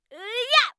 attack_2.wav